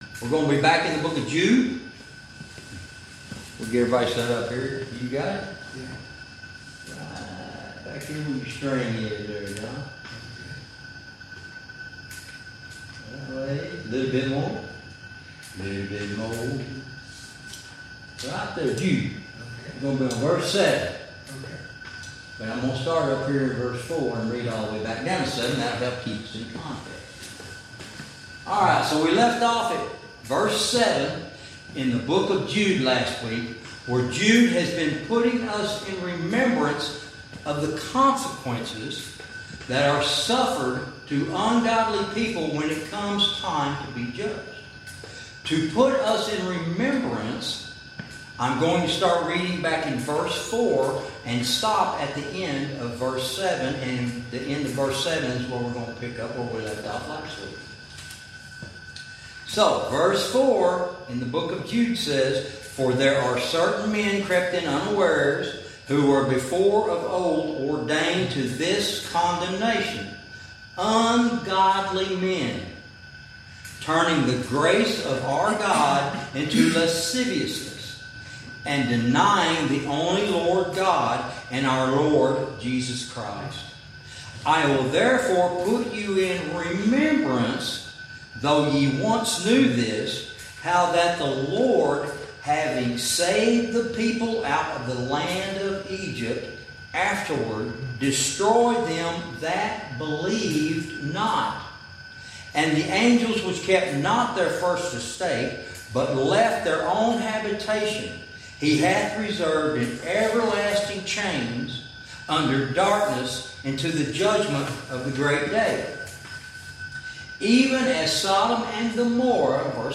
Verse by verse teaching - Lesson 21